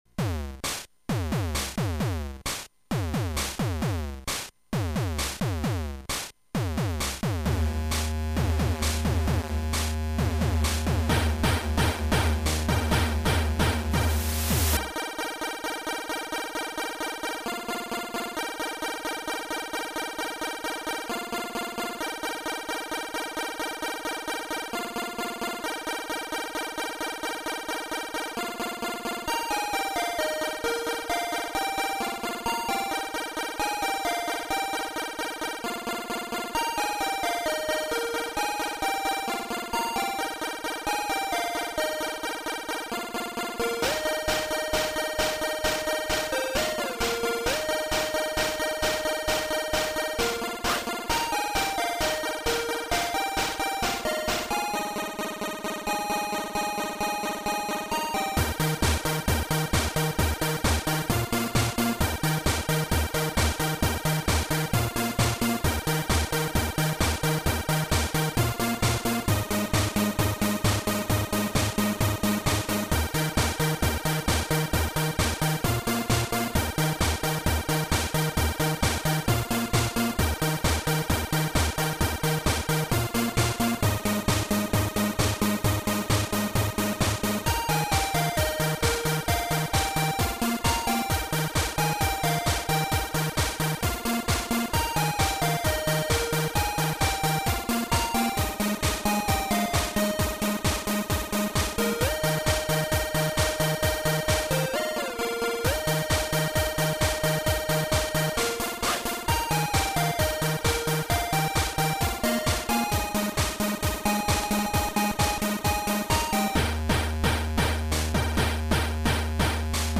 reprise chiptune